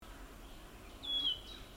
пеночка-рещетка, Phylloscopus sibilatrix
Administratīvā teritorijaValmiera
СтатусПоёт